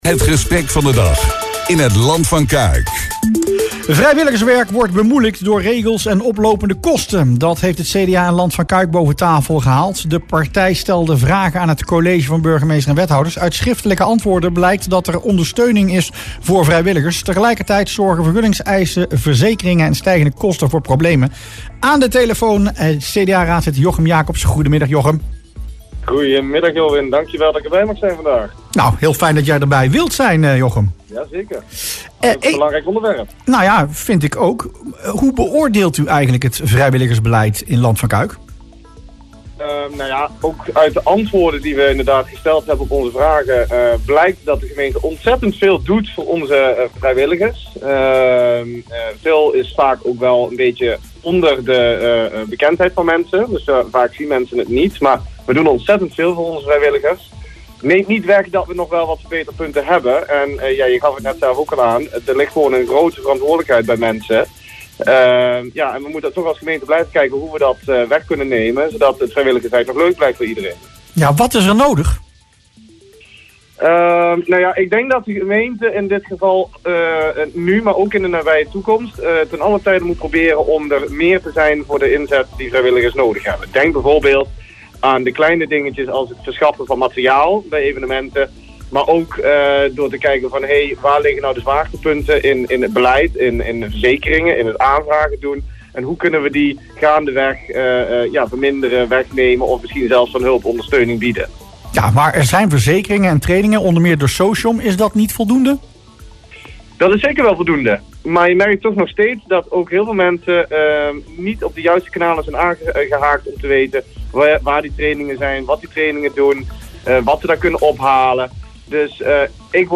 CDA-raadslid Jochem Jacobs in Rusplaats Lokkant